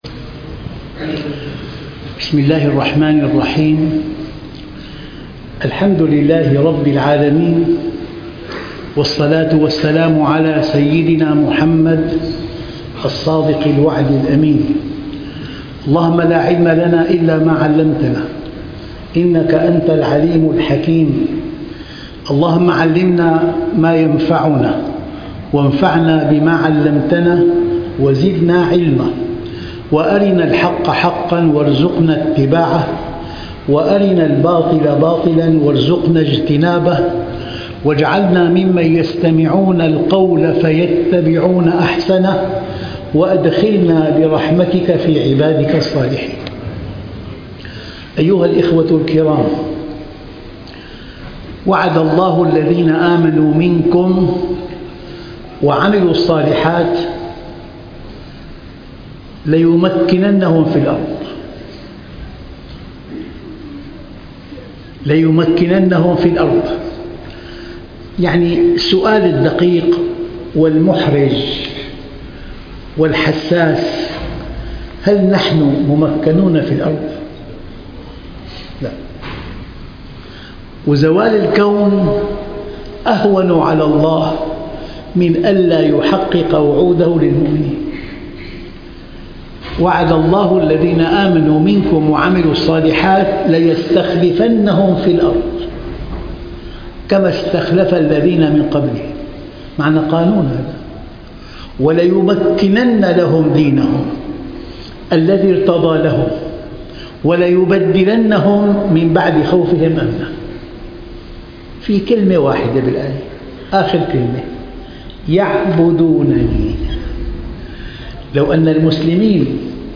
وعود الله تعالى للمؤمنين -عمان - دروس صباحية - الدرس 043 - الشيخ محمد راتب النابلسي